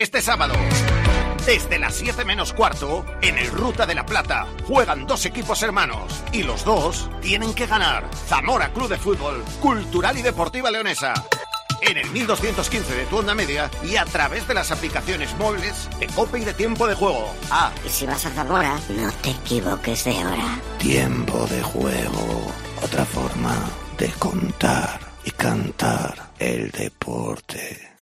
Escucha la cuña promocional del partido Zamora - Cultural el día 11-12-21 a las 19:00 h en el 1.215 OM